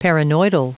Prononciation du mot paranoidal en anglais (fichier audio)